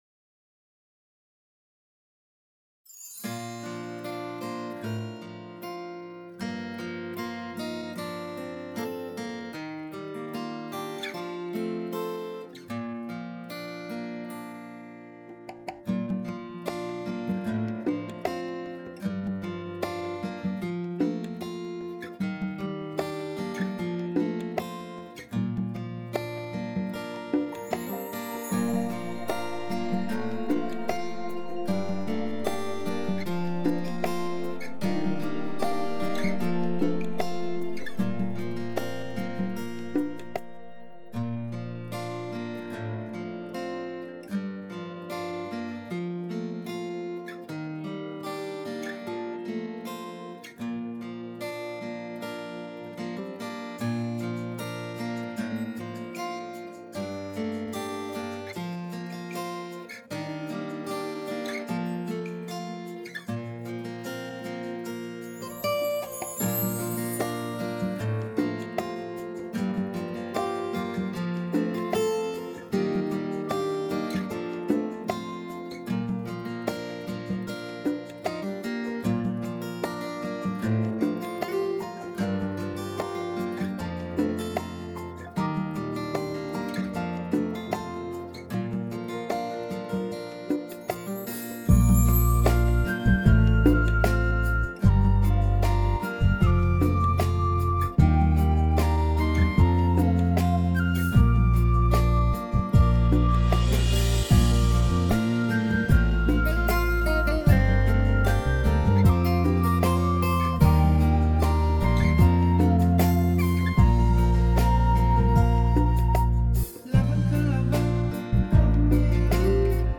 调式 : 降B 曲类